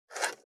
473,ナイフ,調理音,まな板の上,料理,
効果音